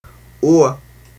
• /uə/ is phonetically [uə] or [ʊə] (
It is a monophthong [uː] for some speakers.